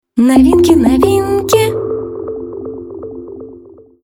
Жен, Вокал/Молодой